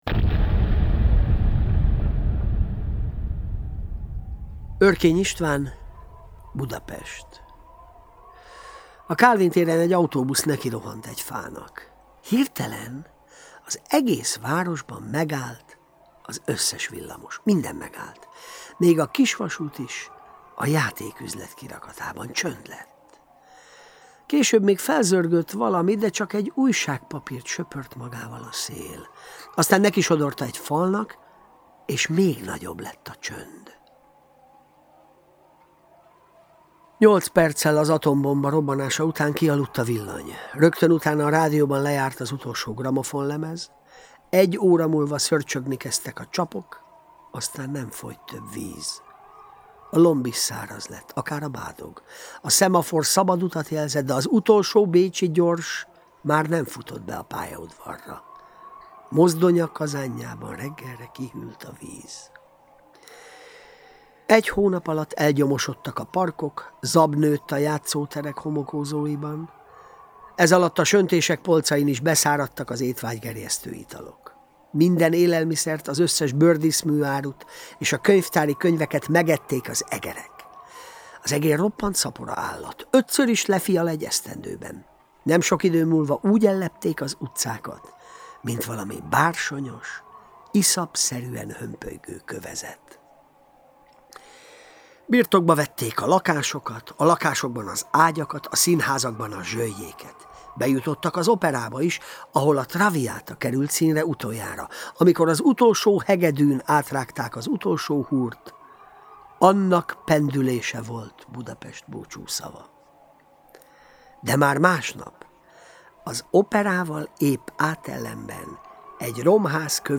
Általános Manda ID 165303 Cím Örkény István: Budapest Leírás A Galaktika folyóirat Hangtár sorozatának 4. részeként 2014-ben jelent meg Örkény István Budapest című írása, melyet Scherer Péter tolmácsolásában hallgathat meg az érdeklődő.
Galaktika_Antolgia_hangosknyv__04_rkny_Istvn__Budapest.mp3